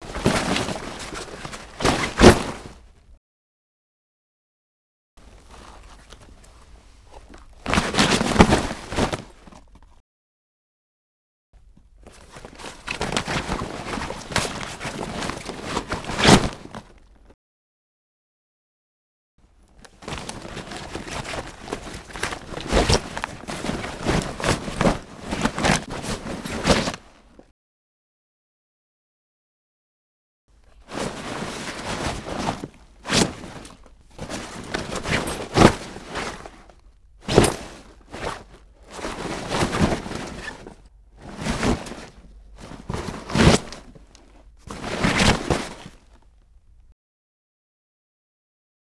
household
Packing Various Objects Into Canvas Duffle Bag